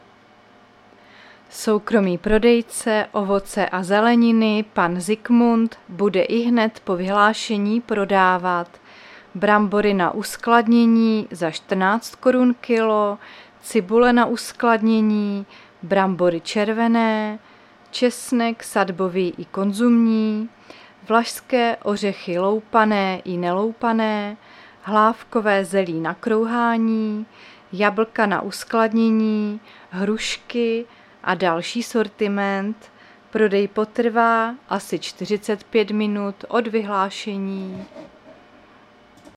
Záznam hlášení místního rozhlasu 21.11.2023